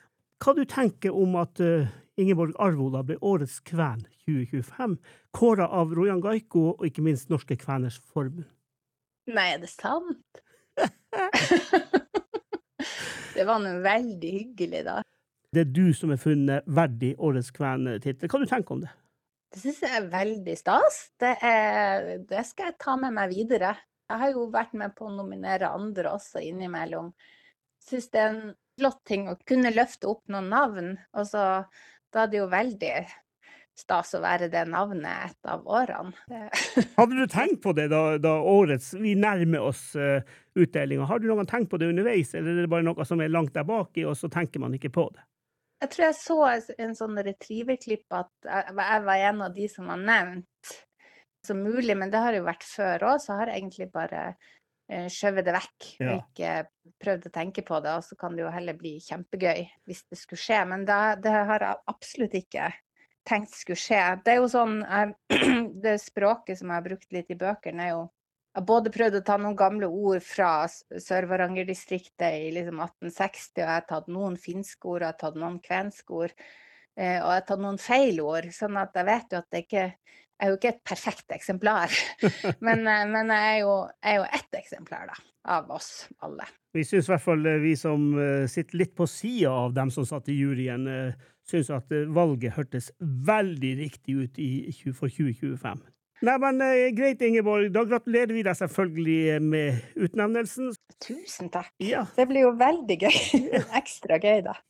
på nordnorsk dialekt hørtes det ut
Her er intervjuet: https